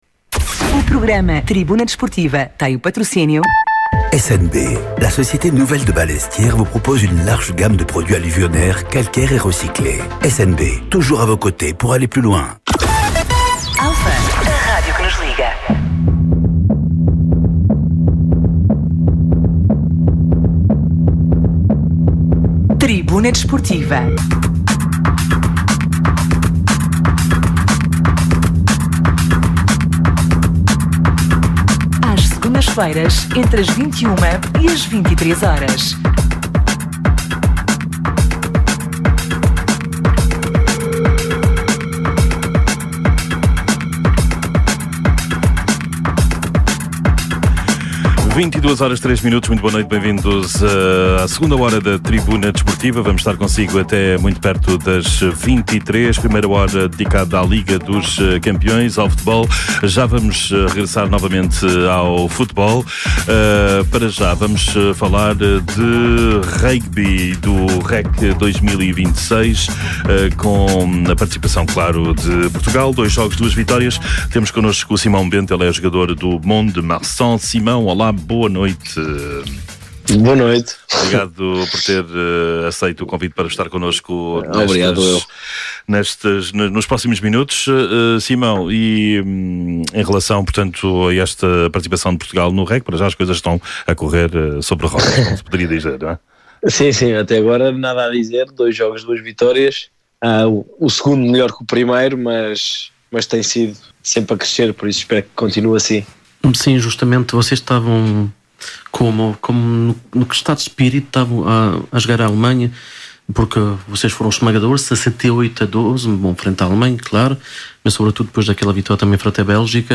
Atualidade Desportiva, Entrevistas.
Tribuna Desportiva é um programa desportivo da Rádio Alfa às Segundas-feiras, entre as 21h e as 23h.